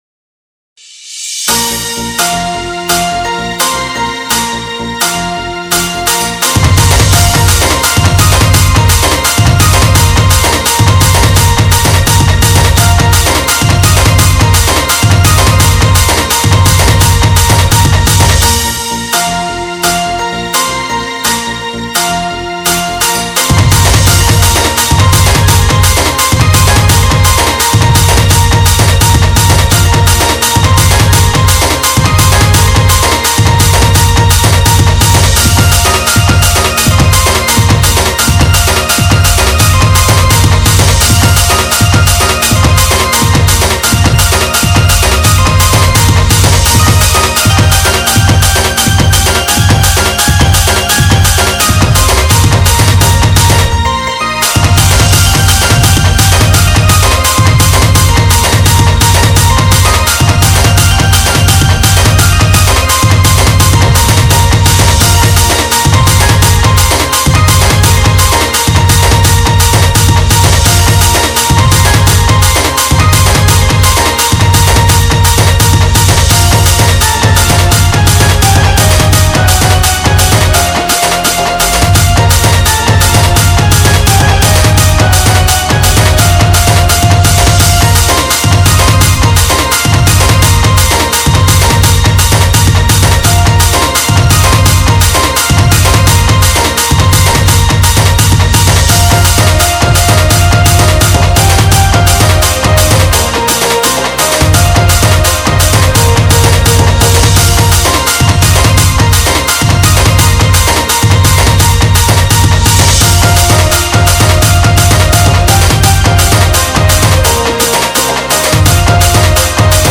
SAMBALPURI INSTRUMENT DJ REMIX